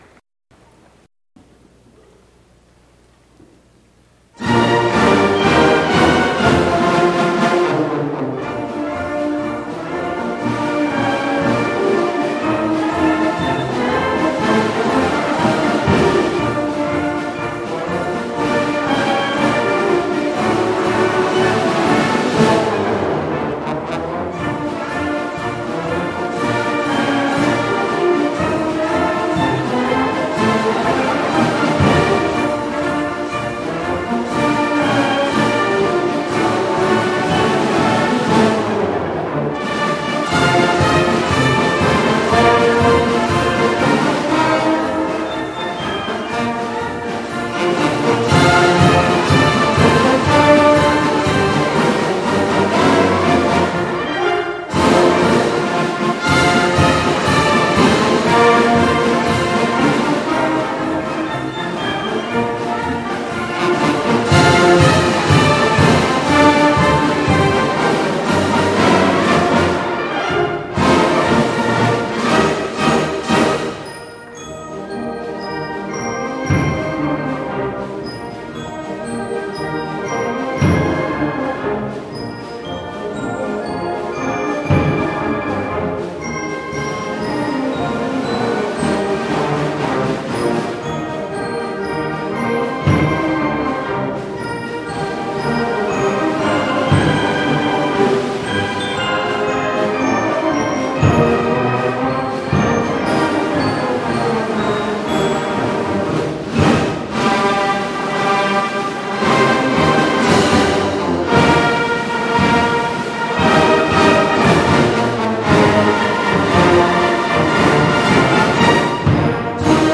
The Windjammers Unlimited Summer Meet July 23-27, 2003 had a significant concert at the amphitheater in Chatauqua, NY.
Enjoy listening to the tunes from that excellent concert in Chatauqua!